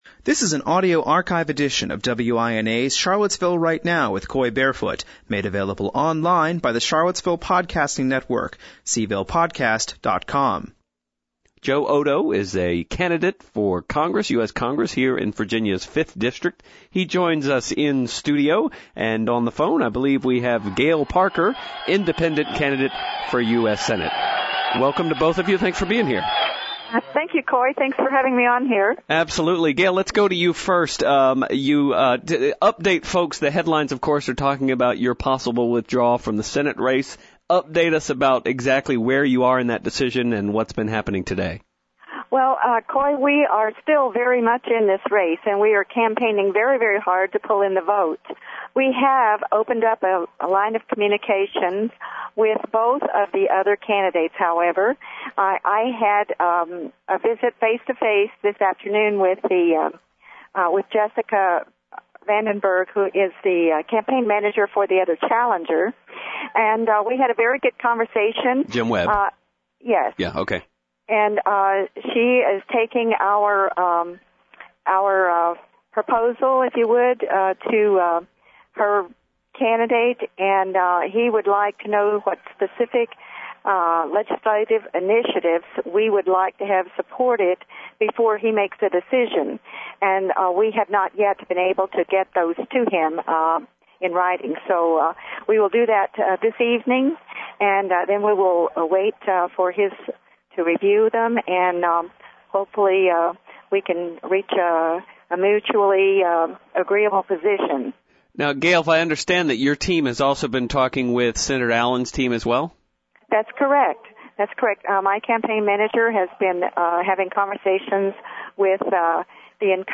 CharlottesvilleGuv,!vDjnjRight Now is broadcast live Monday through Friday on NewsRadio 1070 WINA from 4 to 6pm.